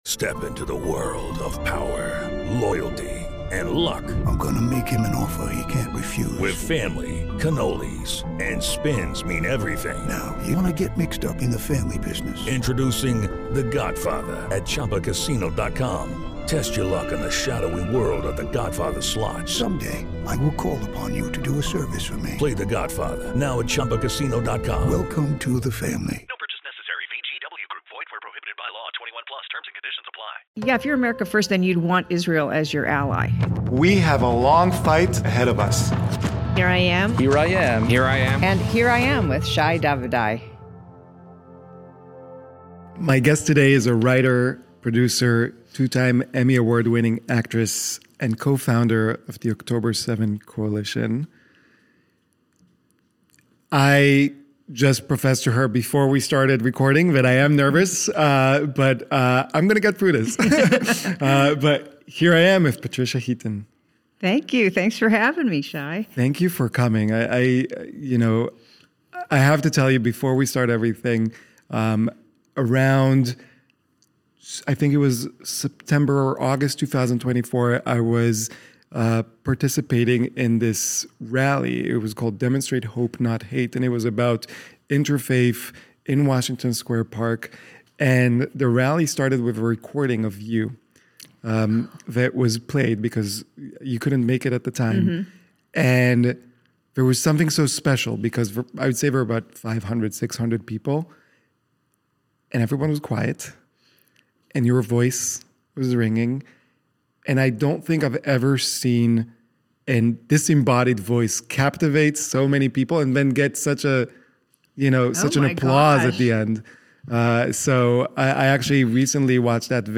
Guest: Actress Patricia Heaton Consider DONATING to help us continue and expand our media efforts.